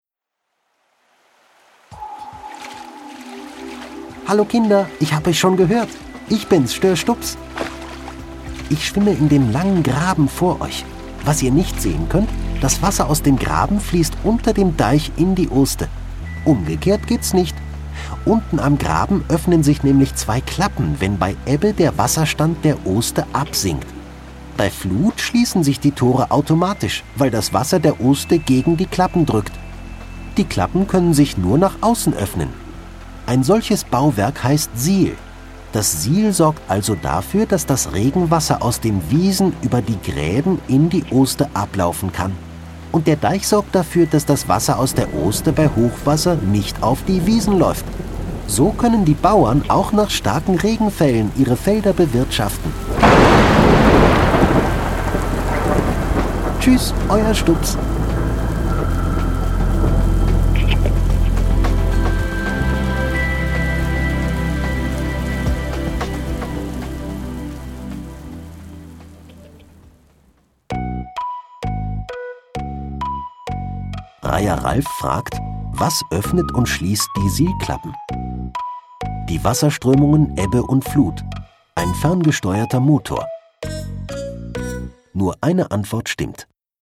Siel Berg - Kinder-Audio-Guide Oste-Natur-Navi